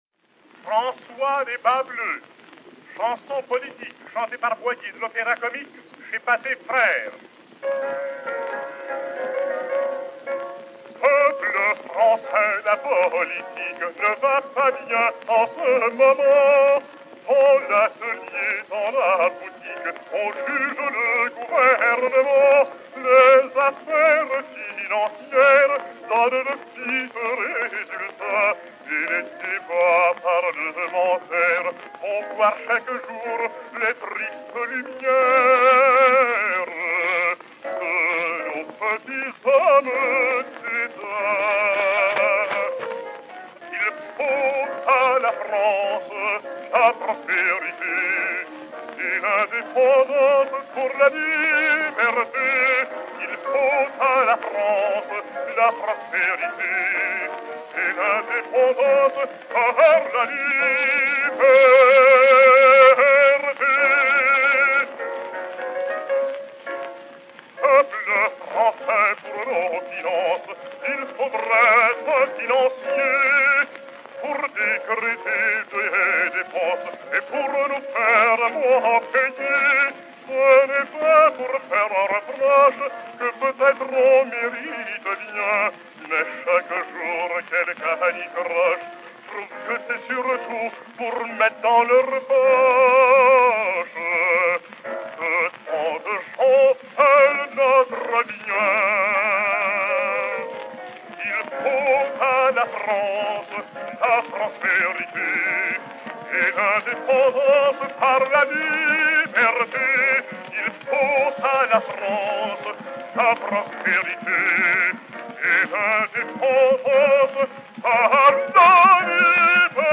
et Piano
Pathé cylindre 1964, enr. en 1902/1904